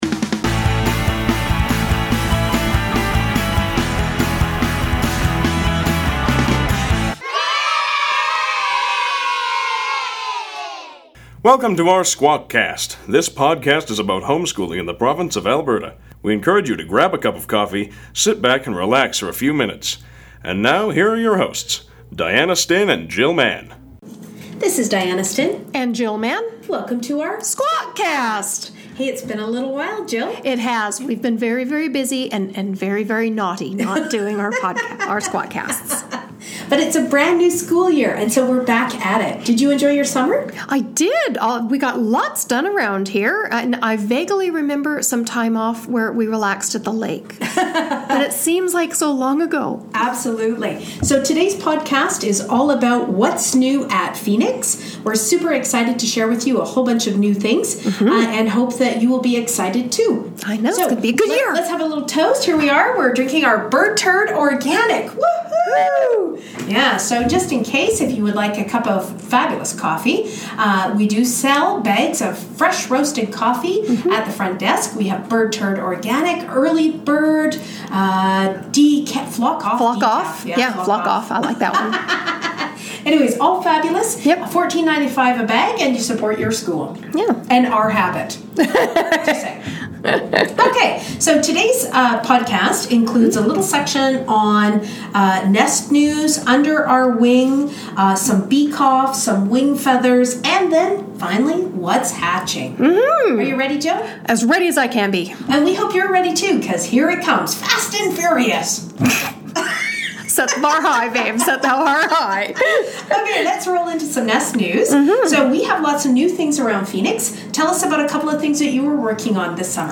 a chat about the new school year, some funding changes from Alberta Education and the cool new things Phoenix has on the go!